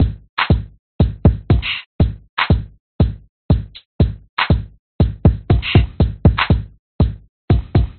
描述：节拍循环
标签： 808 节拍 节拍 hip_hop trip_hop
声道立体声